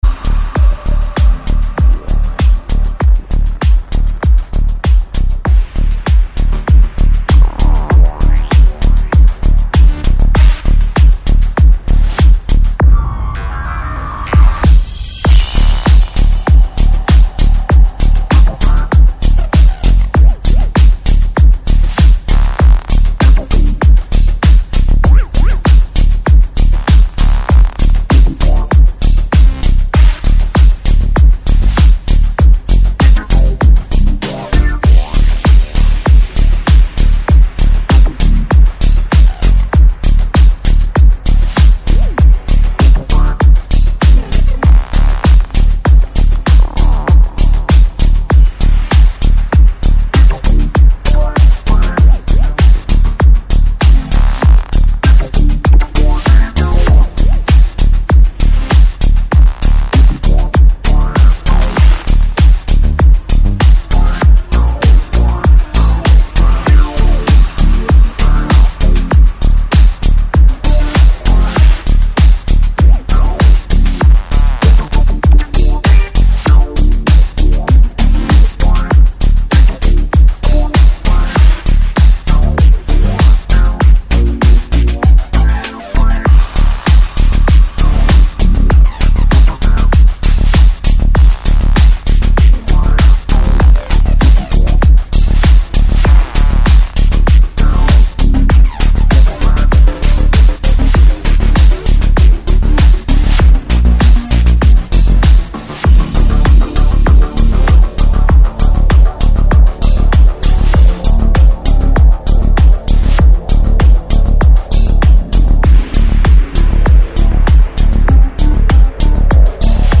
[16/4/2010]重鼓电音 激动社区，陪你一起慢慢变老！